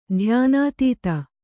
♪ dhyānātīta